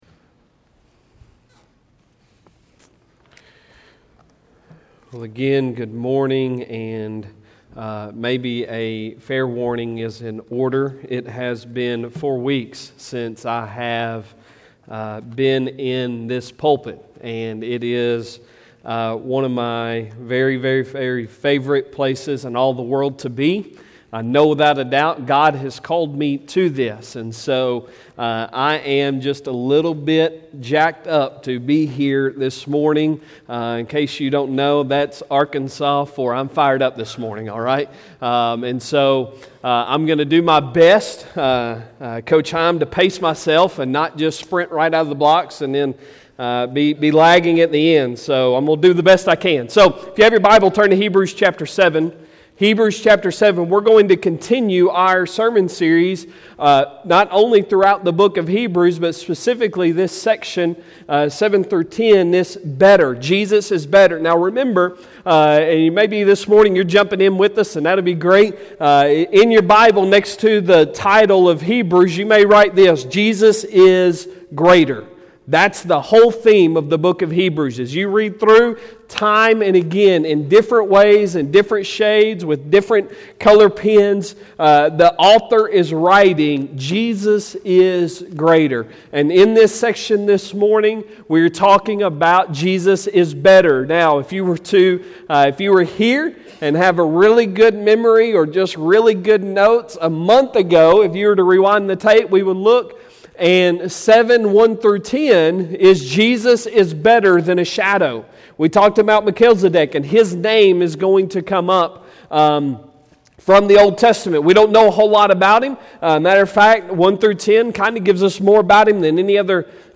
Sunday Sermon August 22, 2021